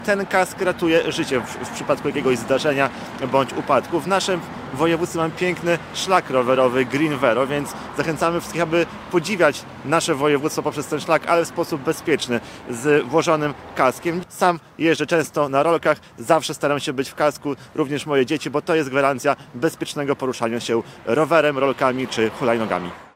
– Nawyk zakładania kasku ratuje życie – mówił marszałek województwa podlaskiego Łukasz Prokorym.